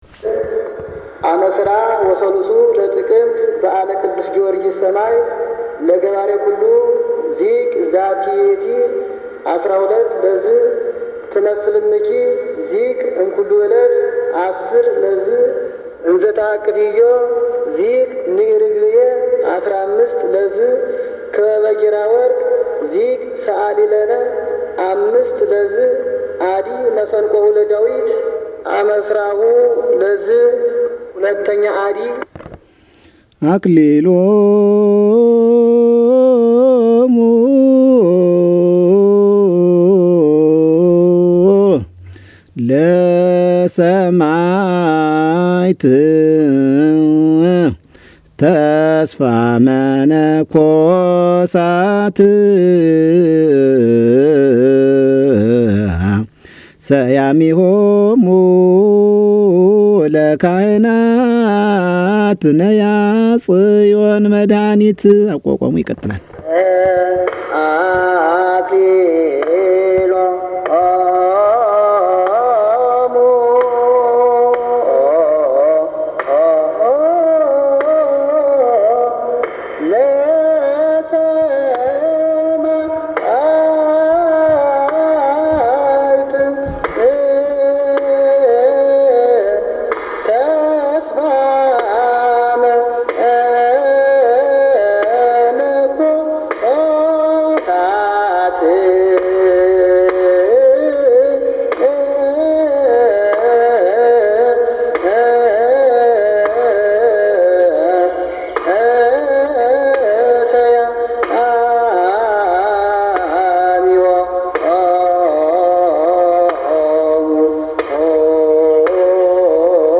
1non stop kum zema.MP3